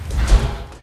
vaultThudNew.ogg